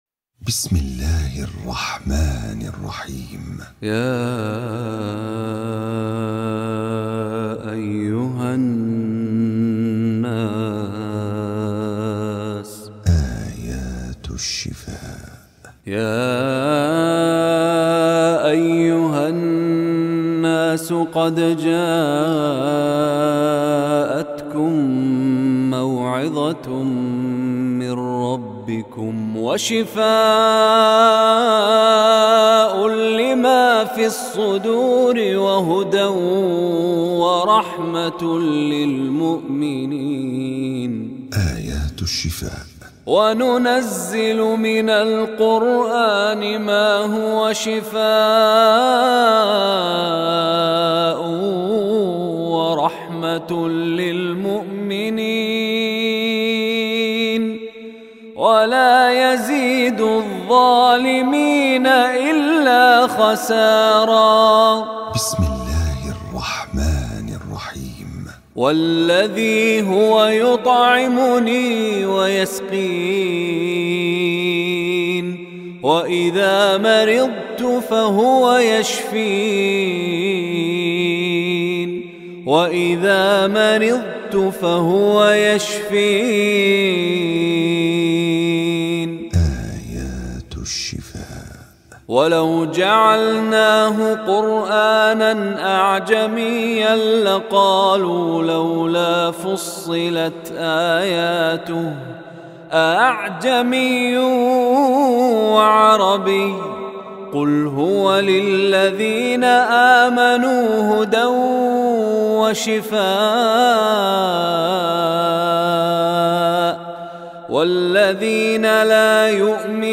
CategoryTilawat
Event / TimeAfter Zuhur Prayer
Shaykh Mishari Rashid Al Afasy is one of the world\'s most popular Quran Reciters. His melodious voice and impeccable tajweed are perfect for any student of Quran looking to learn the correct recitation of the holy book.